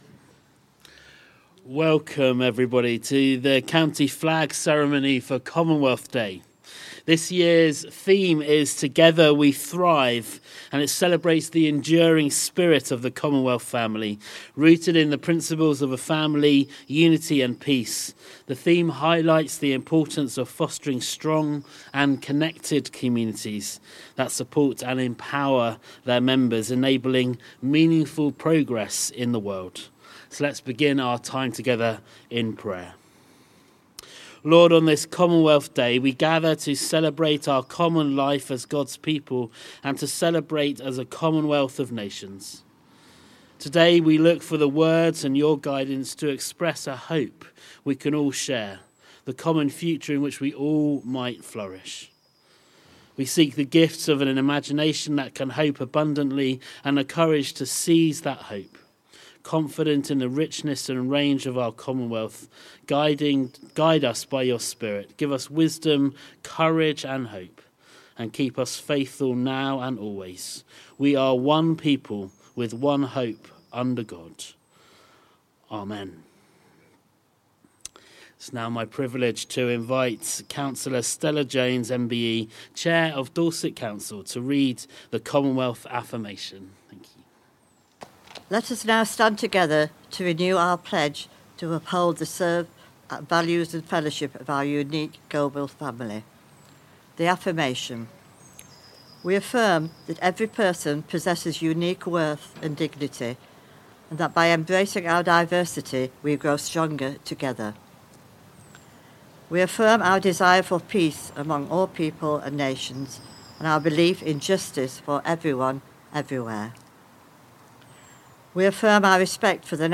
Commonwealth Day 2025 was held on 10th March and a short ceremony was held at the Memorial Corner outside County Hall in Dorchester. Speeches were given by the Chair of Dorset Council Cllr Stella Jones MBE, HM Lord-Lieutenant Mr Michael Dooley LVO and the High Sheriff of Dorset Mr Anthony Woodhouse.